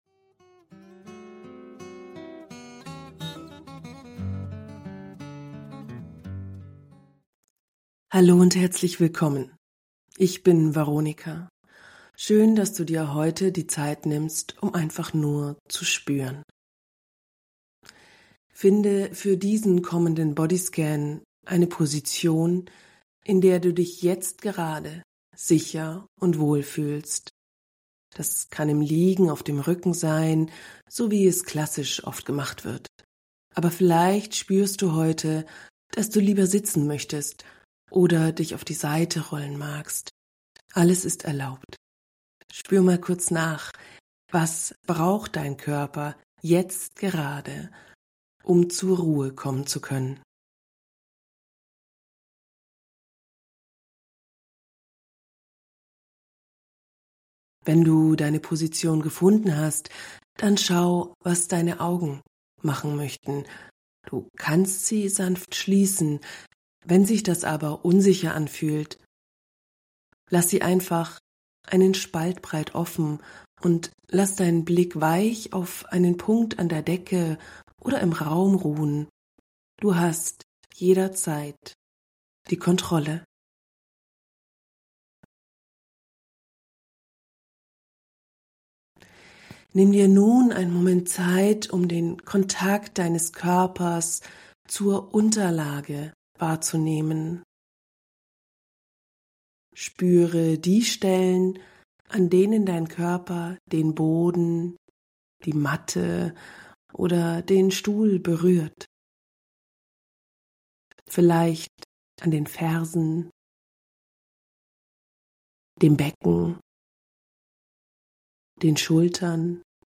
Eine geführte Reise durch deinen Körper, um Sicherheit zu finden, Stress loszulassen und tief zu entspannen. Ohne Zwang, mit viel Sanftheit.